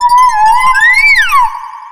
Cri de Givrali dans Pokémon X et Y.